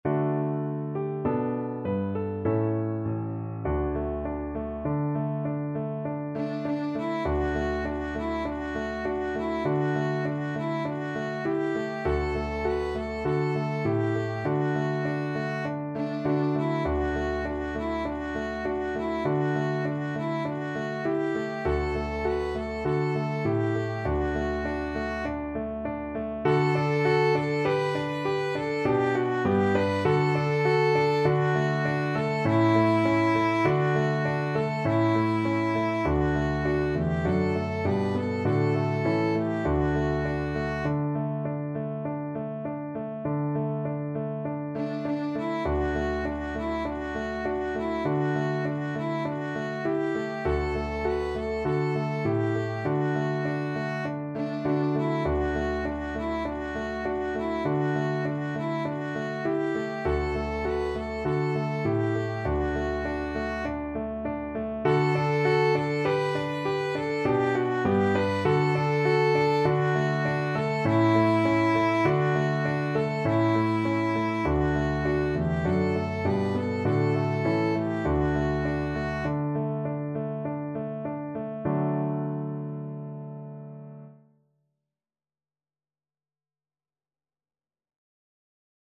Christmas Christmas Violin Sheet Music Es ist fur uns eine Zeit angekommen
Free Sheet music for Violin
Violin
4/4 (View more 4/4 Music)
D major (Sounding Pitch) (View more D major Music for Violin )
Moderato
Traditional (View more Traditional Violin Music)